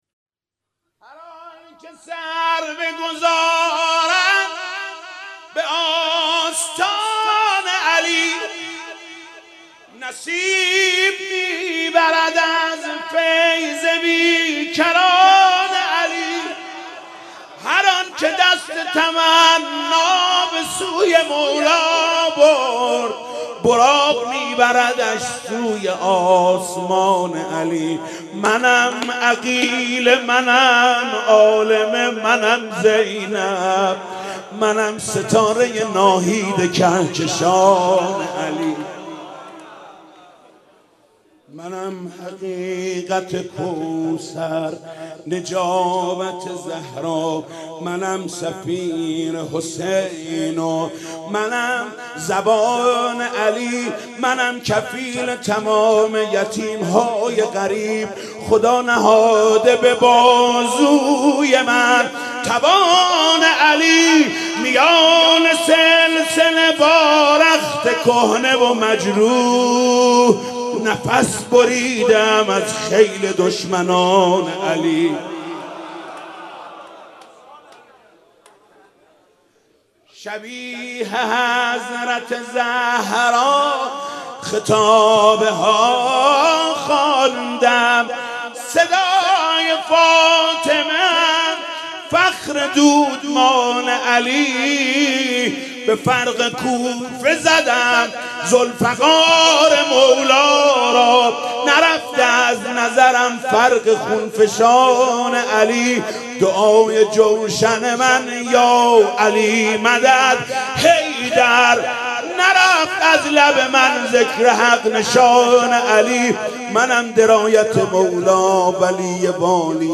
مداحی اربعین